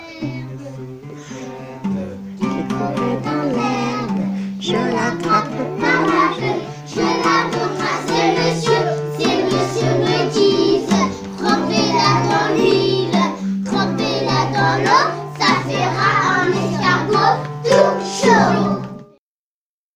Ecole Maternelle Publique Floréal de Saint Denis Un peu de musique: l'OUD